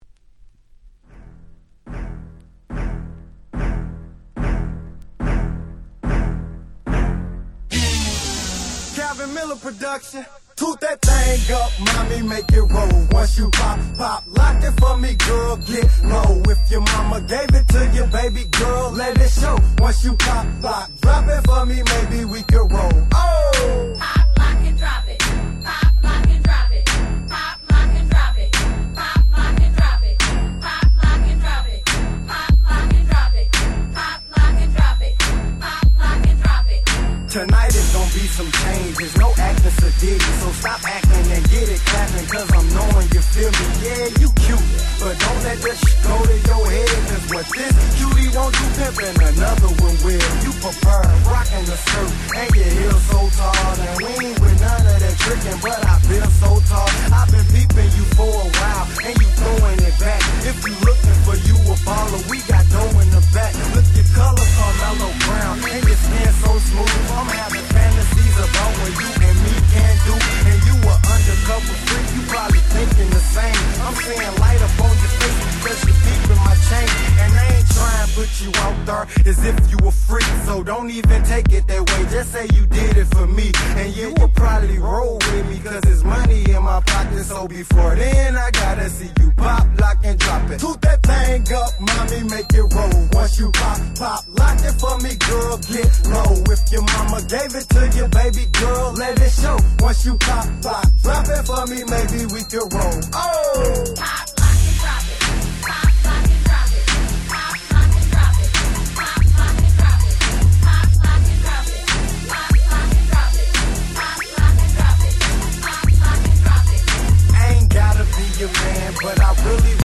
06' Super Hit Southern Hip Hop !!